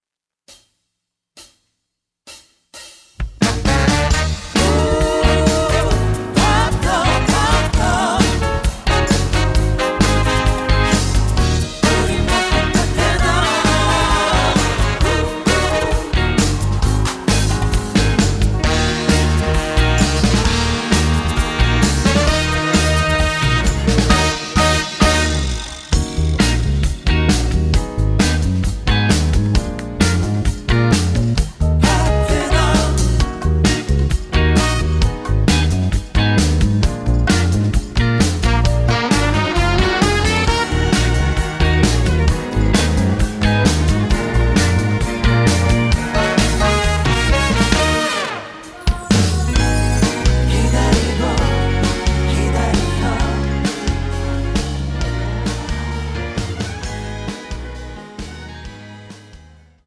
음정 -1키 3:17
장르 가요 구분 Voice MR
보이스 MR은 가이드 보컬이 포함되어 있어 유용합니다.